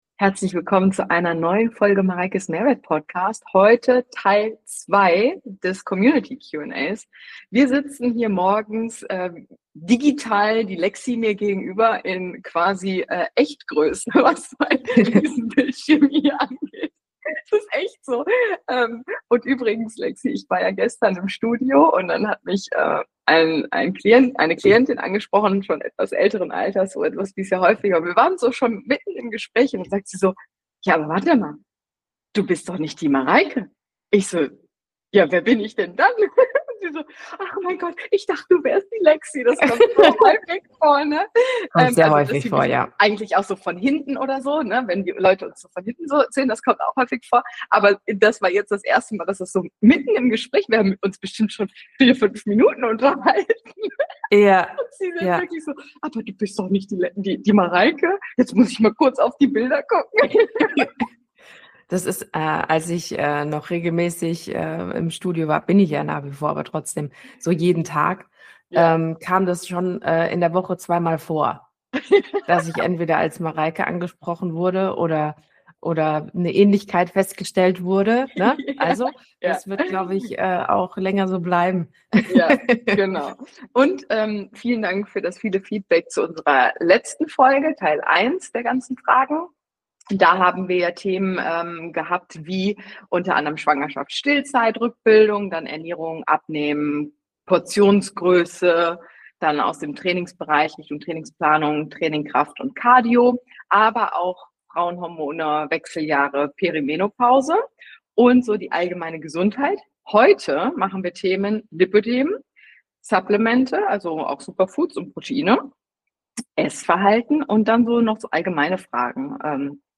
Community Q&A – Fitness, Gesundheit & ehrliche Antworten (Teil 2)